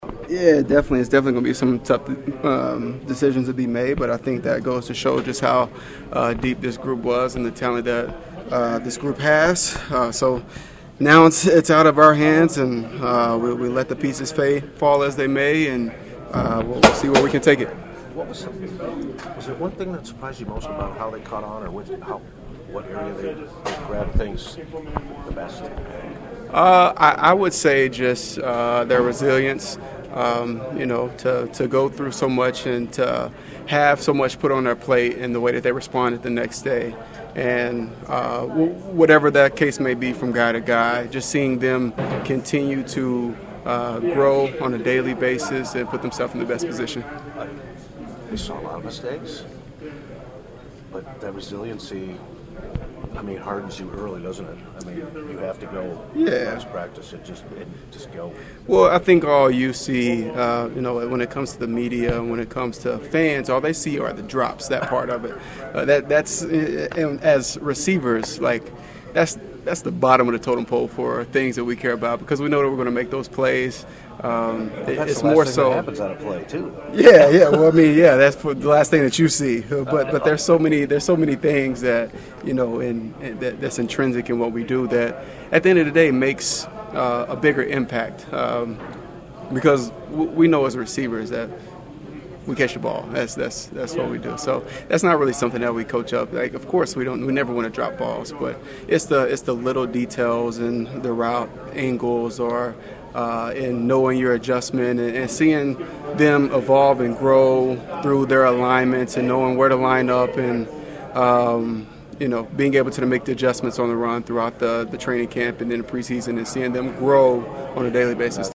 Five weeks ago, Randall Cobb knew he would have to show the way with the young pass catchers and I caught up with him in the locker room after practice.